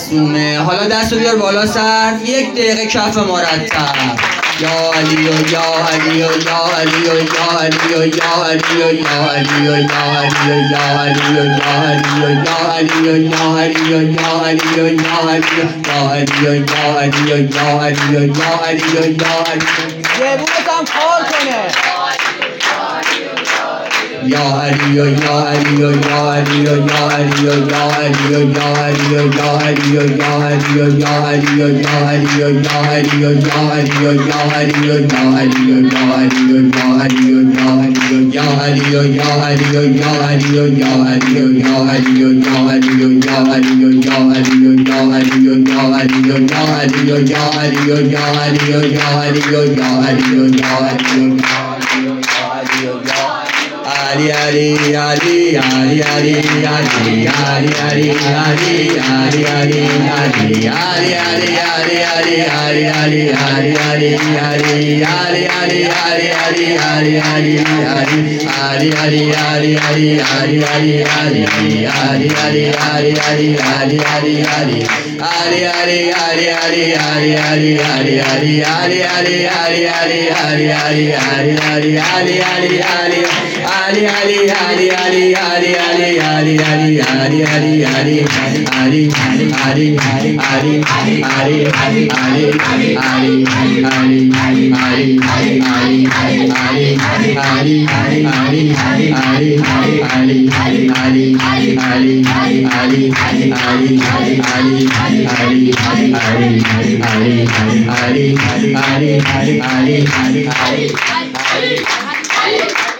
ذکر